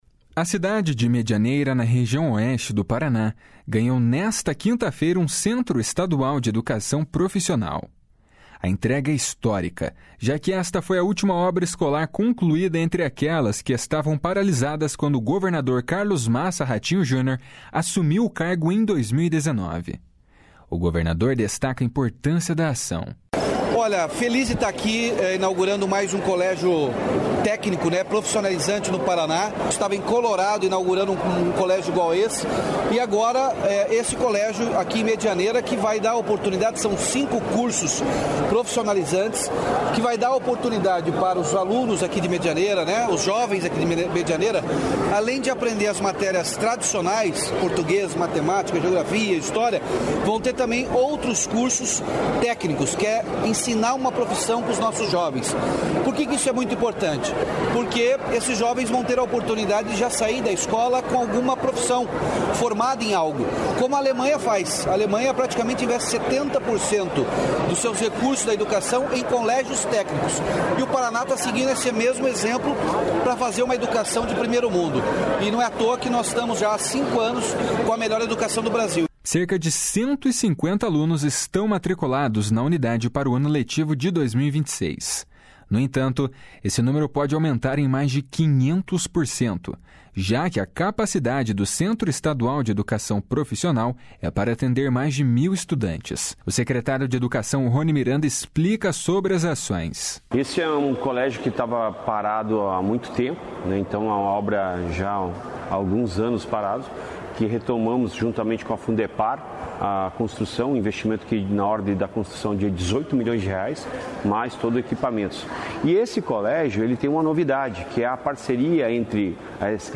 O governador destaca a importância da ação. // SONORA RATINHO JUNIOR //
O secretário de Educação, Roni Miranda, explica sobre as ações.
O prefeito Antonio França, ressalta a gratidão da região.